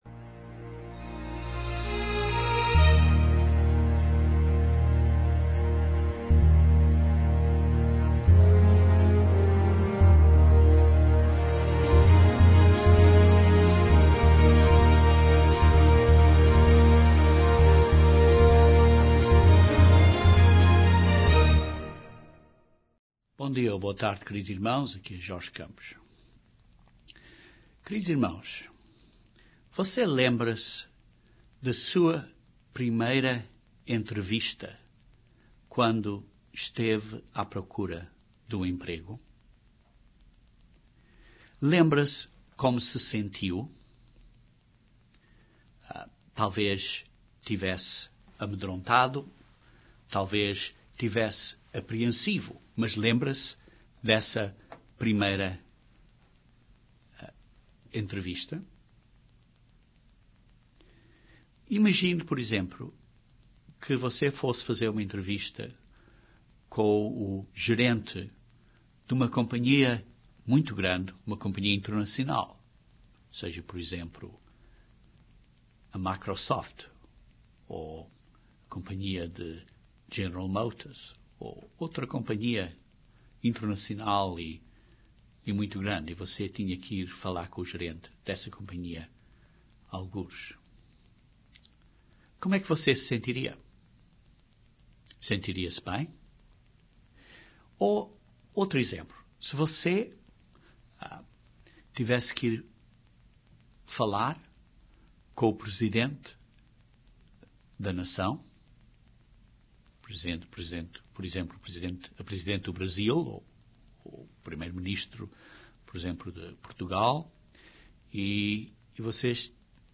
Este sermão descreve alguns pontos importantes para se preparar para essa reunião.